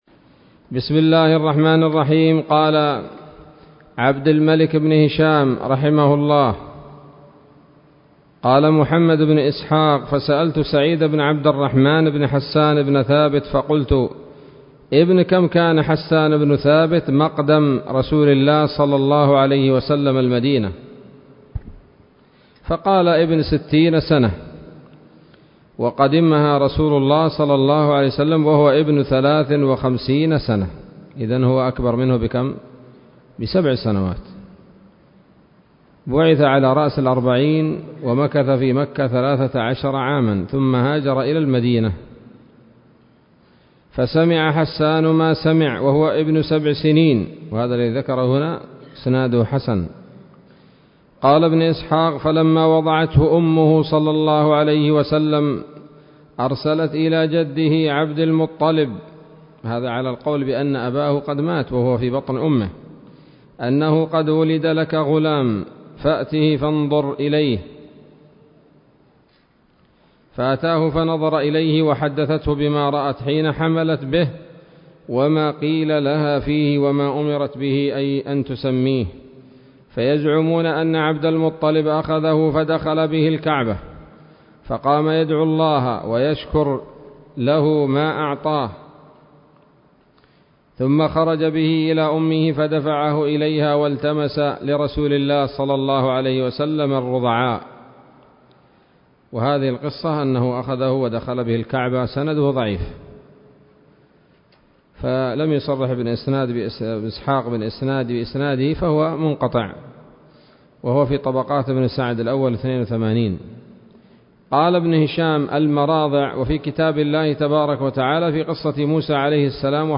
الدرس الثالث عشر من التعليق على كتاب السيرة النبوية لابن هشام